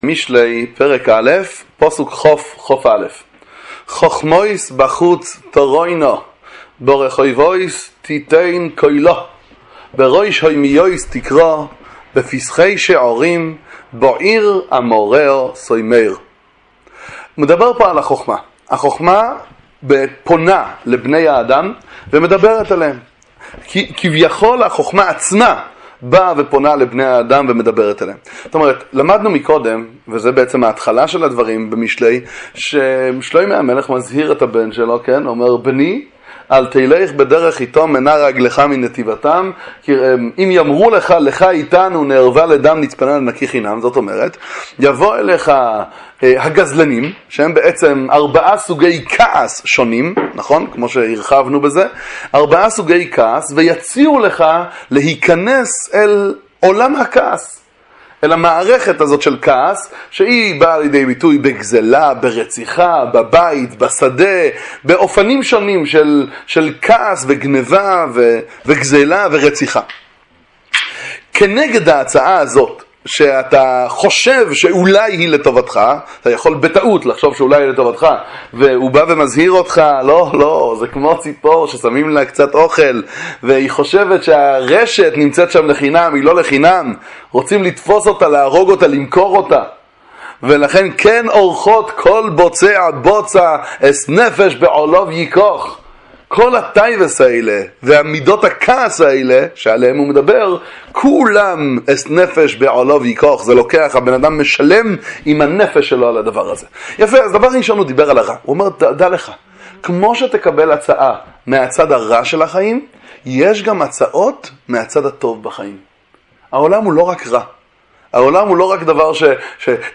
שיעורי תורה לצפייה בספר משלי עם ביאור הגר"א, דברי תורה מהגאון מוילנא